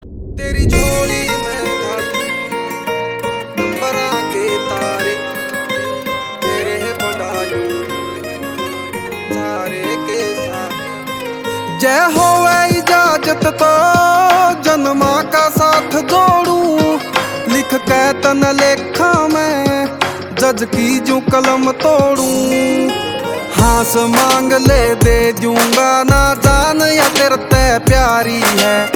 HARYANVI MP3 SONG